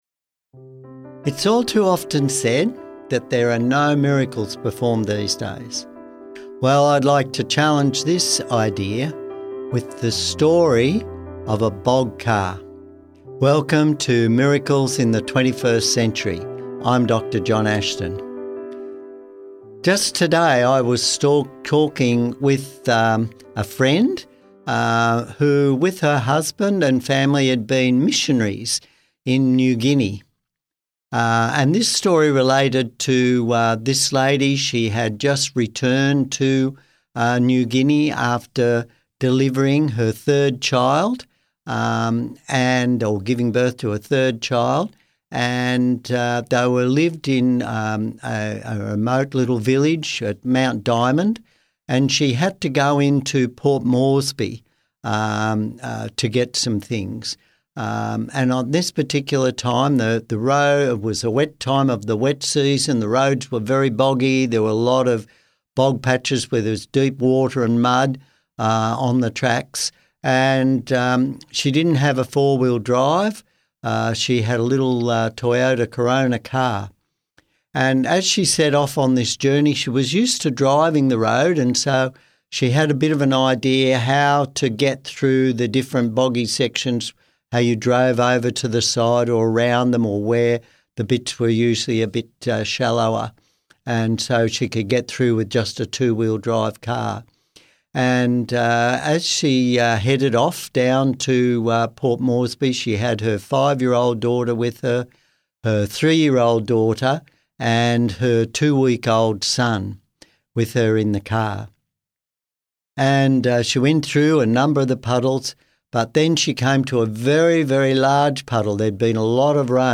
True miracle story of answered prayer and modern miracles today. A Christian missionary mother in Papua New Guinea gets her car hopelessly stuck in deep mud with her children, until a simple prayer changes everything.